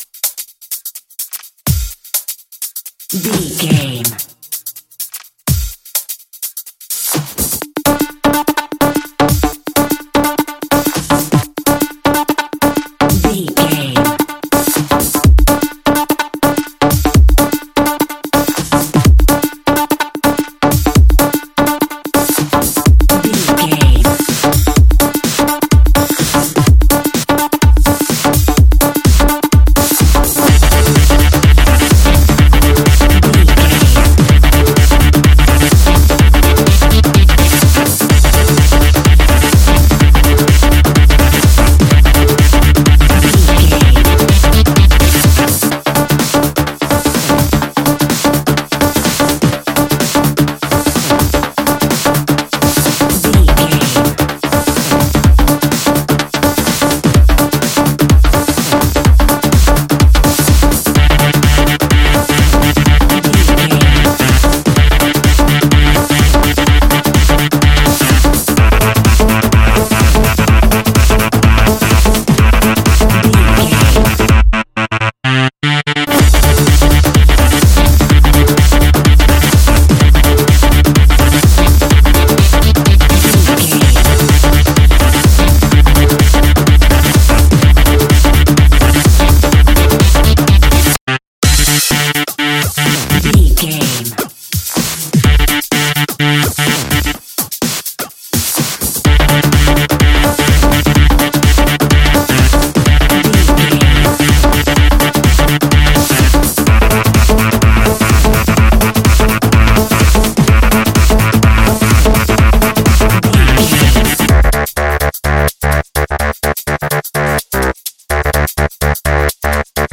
Aeolian/Minor
groovy
uplifting
driving
energetic
dark
drum machine
synthesiser
disco
upbeat
instrumentals
funky guitar
clavinet
synth bass
horns